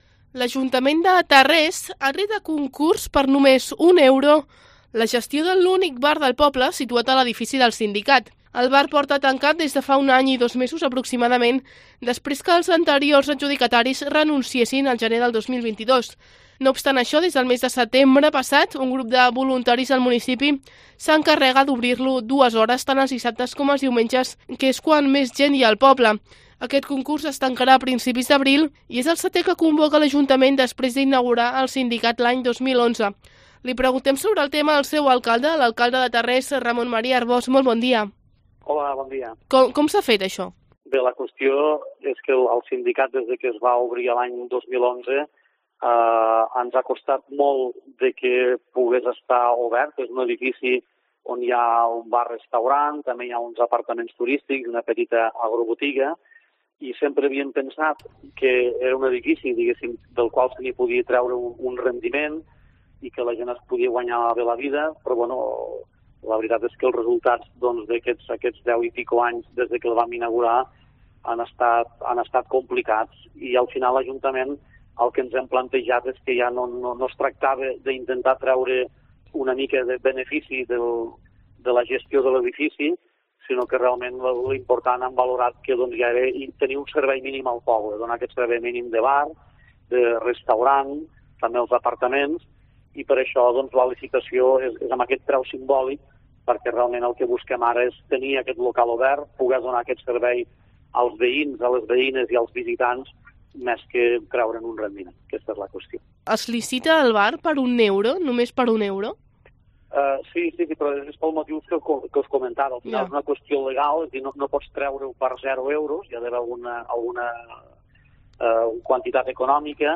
Entrevista al alcalde de Tarrés, Ramón Maria Arbós